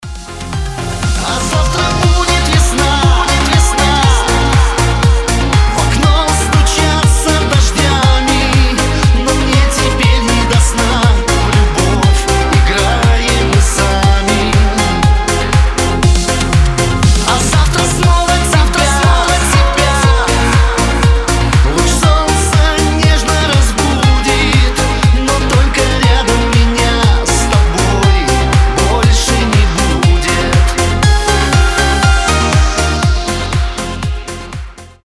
• Качество: 320, Stereo
мужской голос
грустные